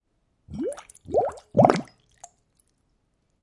描述：工作室录制的声音带有AT4047麦克风。
标签： 液体 泡沫 起泡 气泡
声道立体声